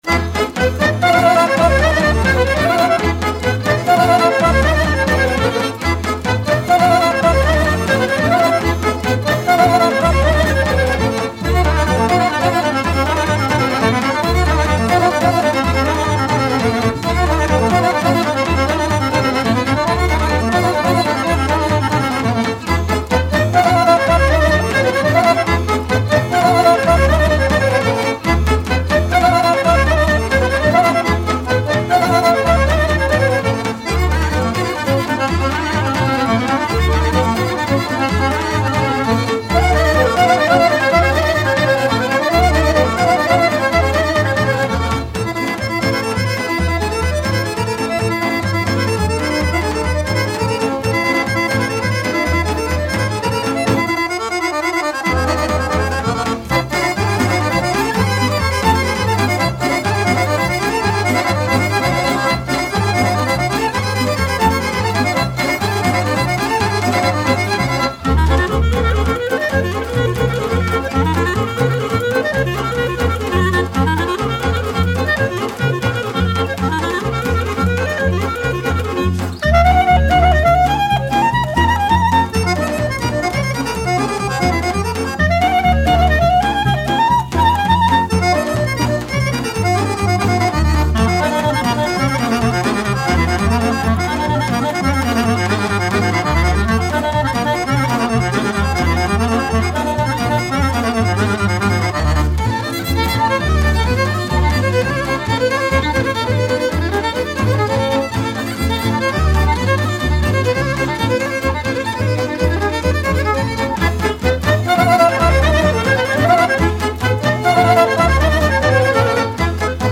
Bulgarian Instrumental